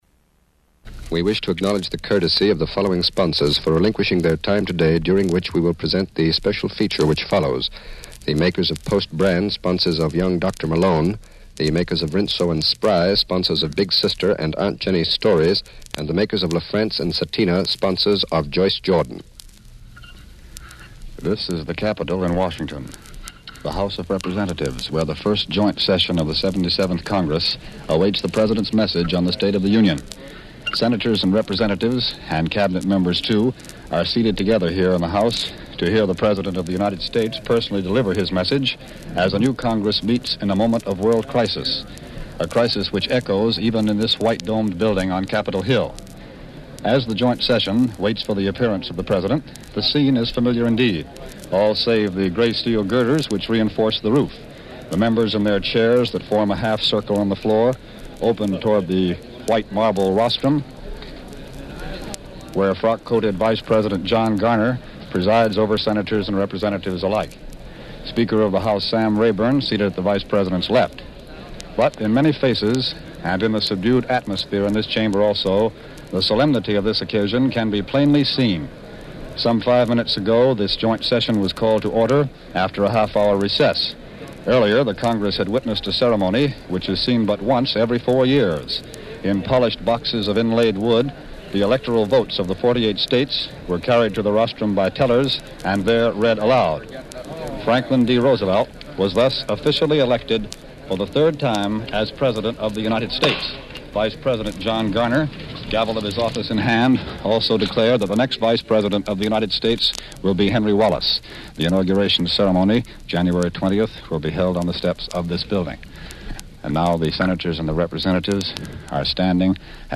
State of the Union address. 1941 January 6